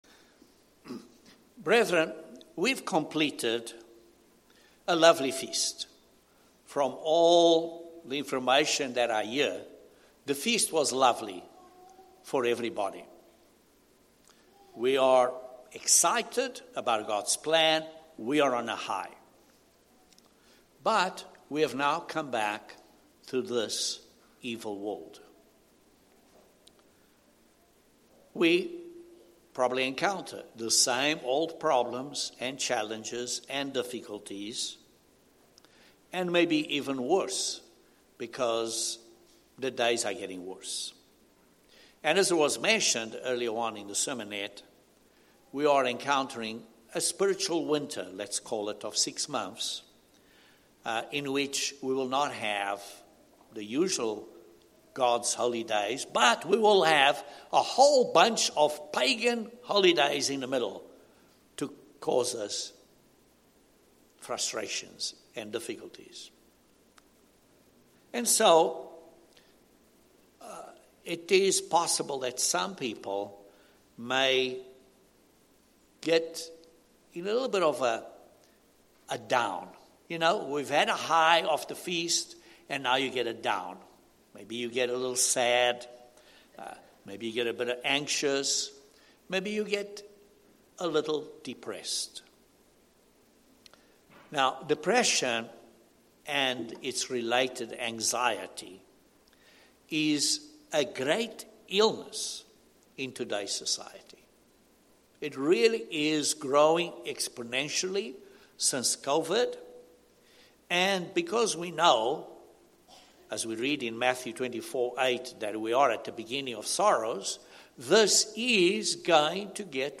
Depression and anxiety are major illnesses nowadays and are growing exponentially. This sermon addresses the issue that situations around us are one of the main causes of depression and looks at three areas that we can control with God's help to fight this illness.